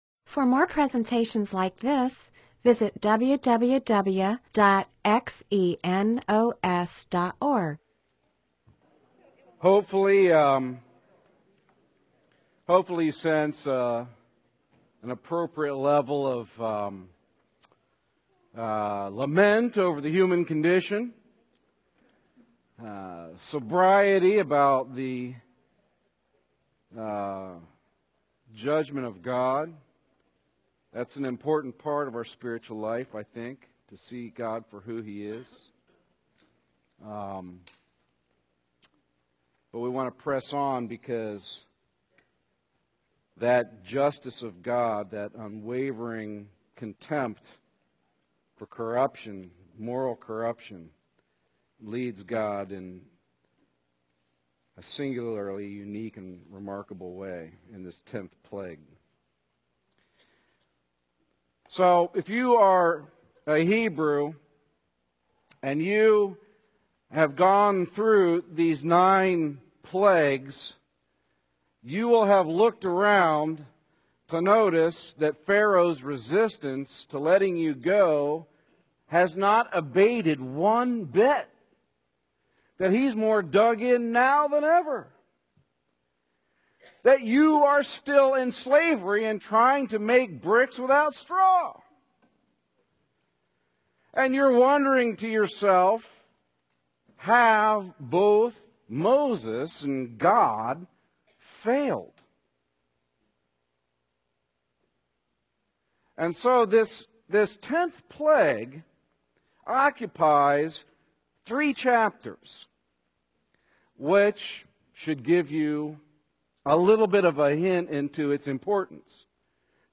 Bible teaching (presentation, sermon) on Exodus 11:1-13:16